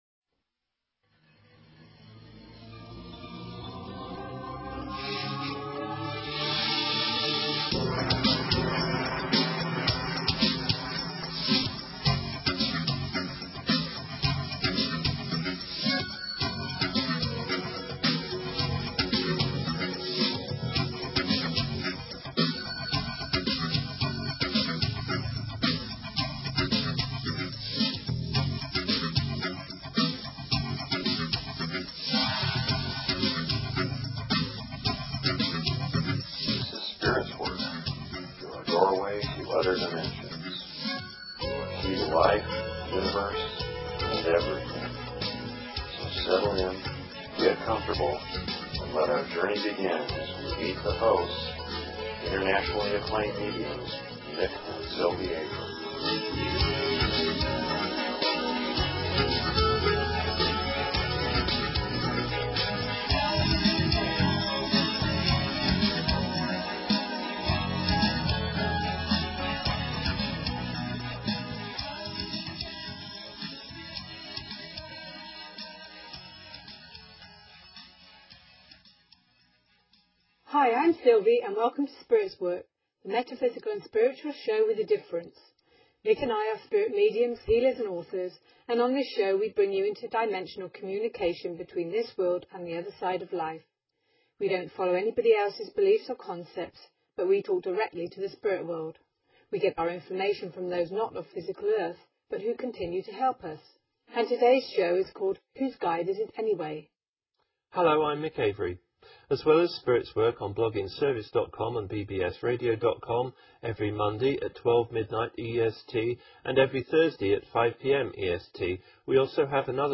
Talk Show Episode, Audio Podcast, Spirits_Work and Courtesy of BBS Radio on , show guests , about , categorized as